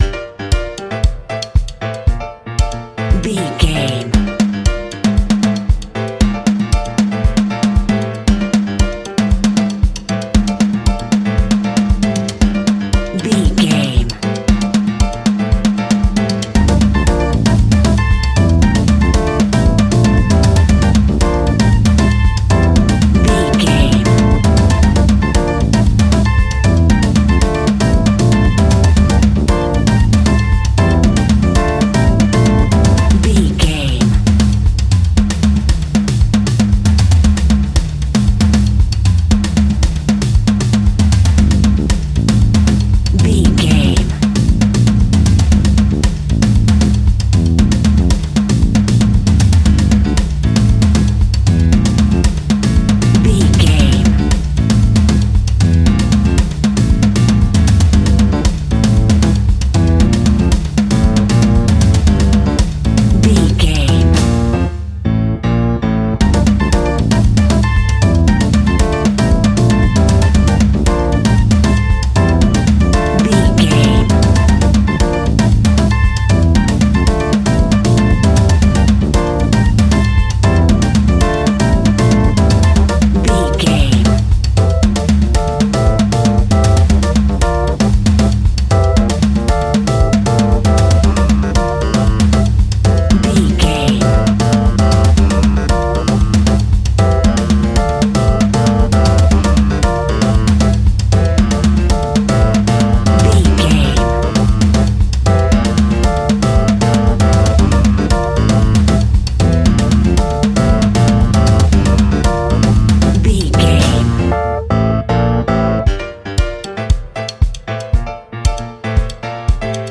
Aeolian/Minor
flamenco
latin
salsa
uptempo
bass guitar
percussion
brass
saxophone
trumpet